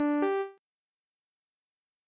Звуки ввода пароля